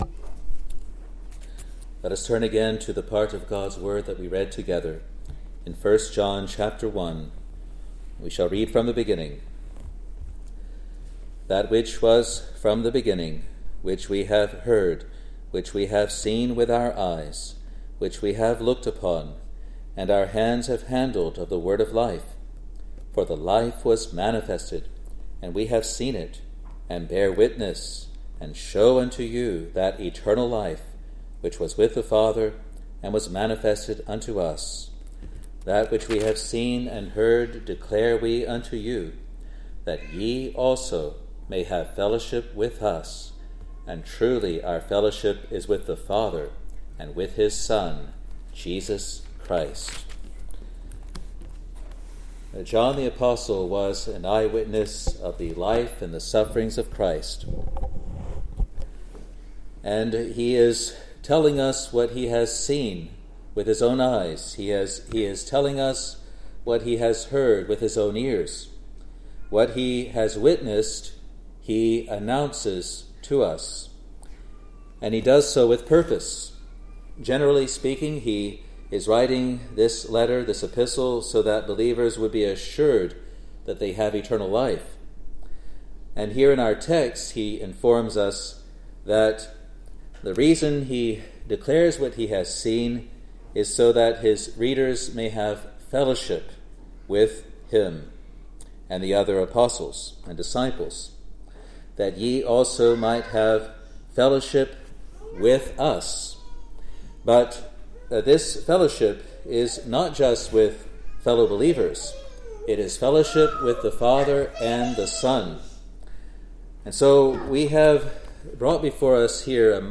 Sermons | Free Presbyterian Church of Scotland in New Zealand